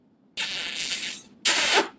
kiss.ogg.mp3